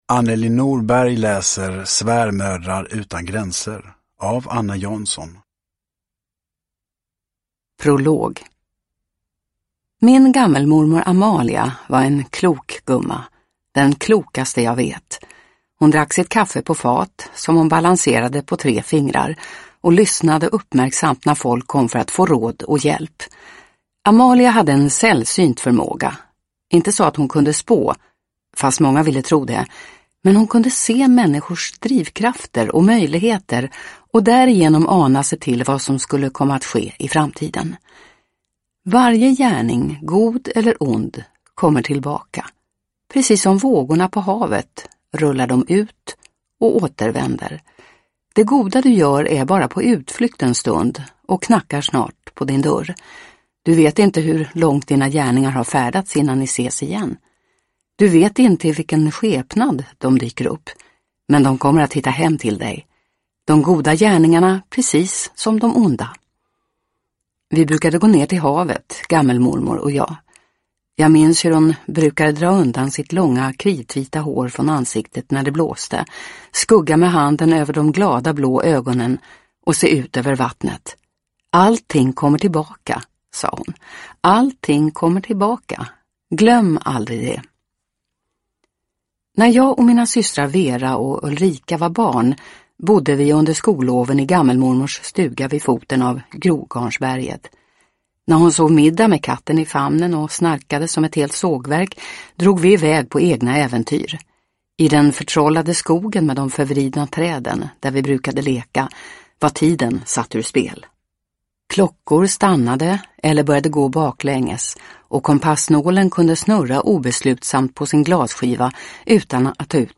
Svärmödrar utan gränser – Ljudbok – Laddas ner